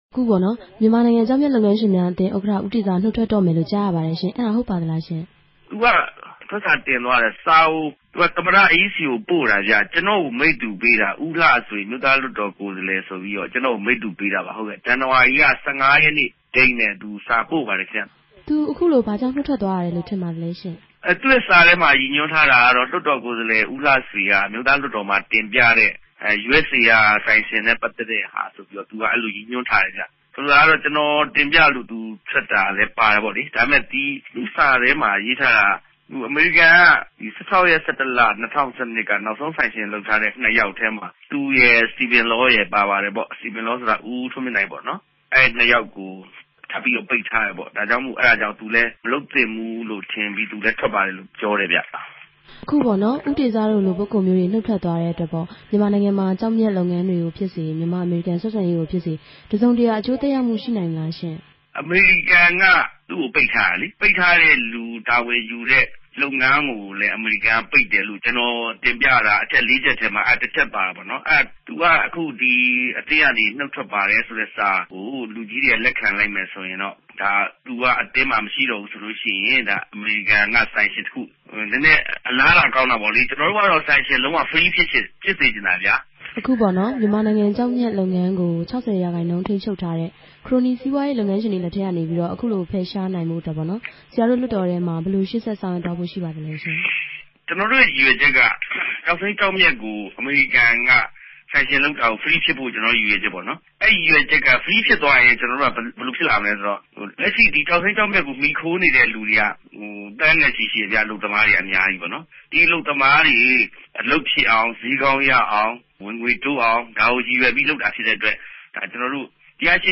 ကျောက်မျက်လုပ်ငန်းအသင်း ဥက္ကဌ ဦးတေဇ နှုတ်ထွက်သွားခြင်းအပေါ် ဆက်သွယ်မေးမြန်းချက်